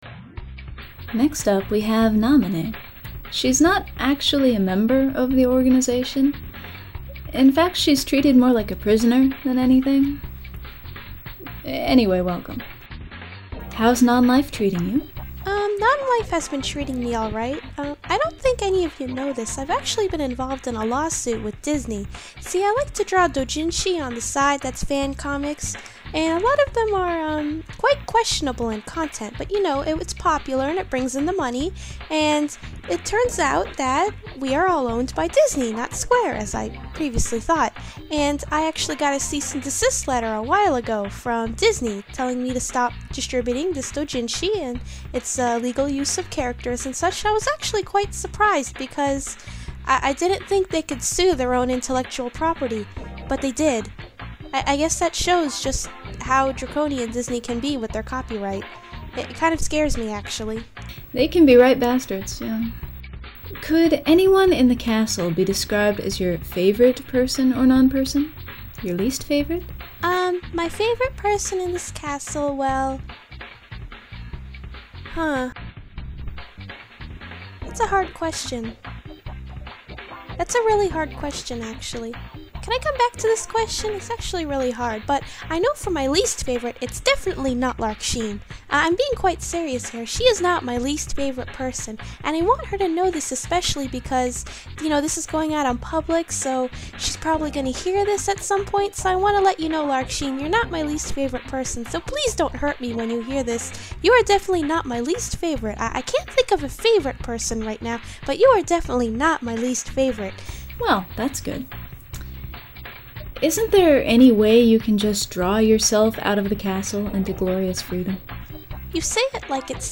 Character Interviews
NamineInterview.mp3